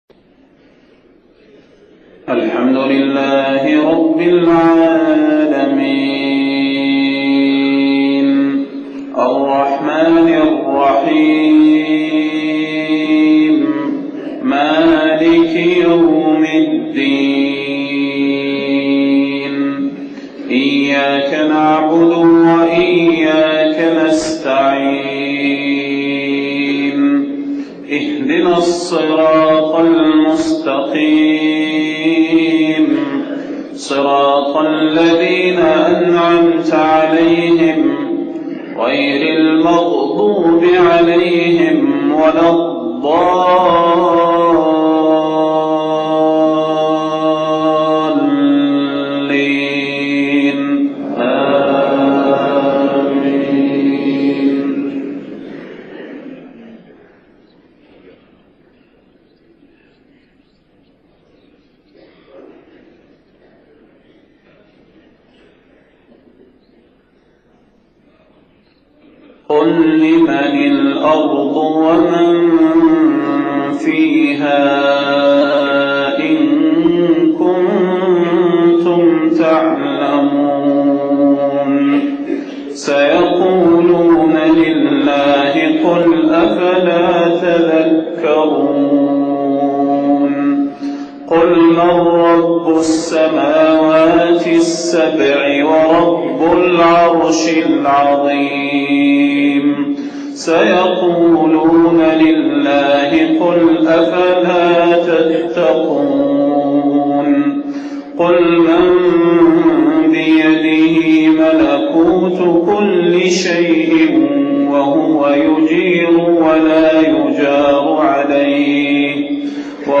صلاة الفجر 14 محرم 1430هـ خواتيم سورة المؤمنون 84-118 > 1430 🕌 > الفروض - تلاوات الحرمين